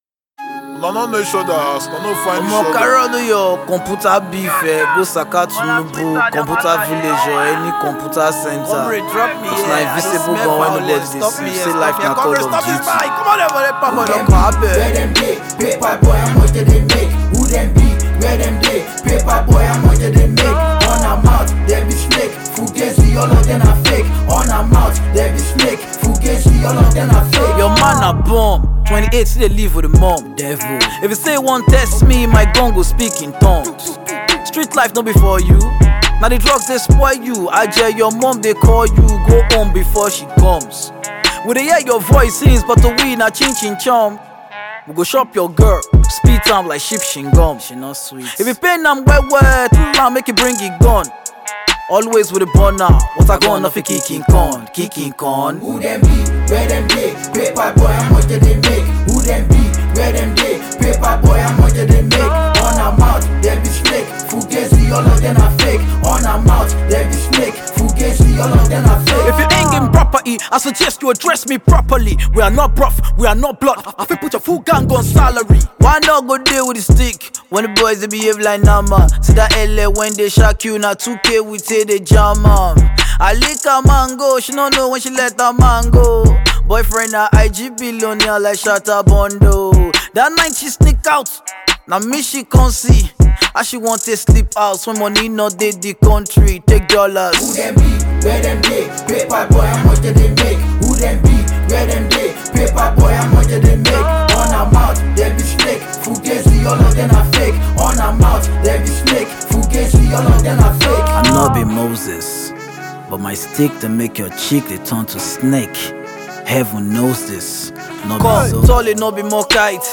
Nigerian rapper and singer